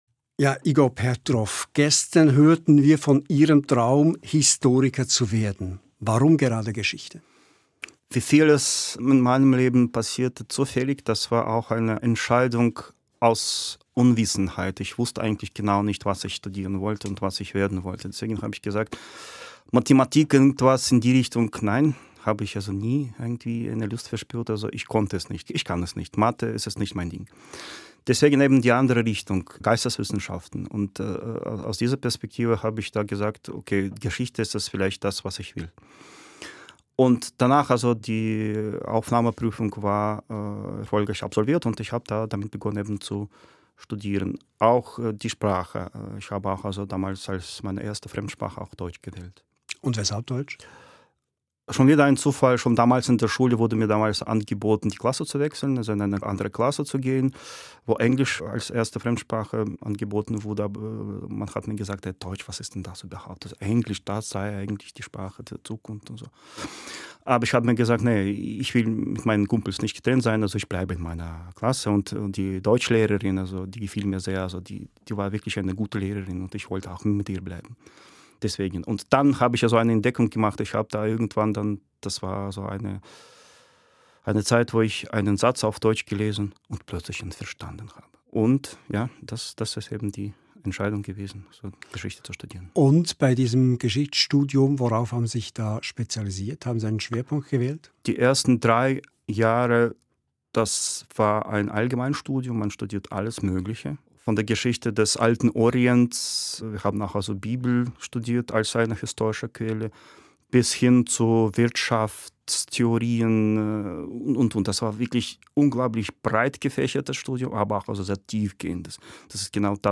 Episode 3 des Gesprächs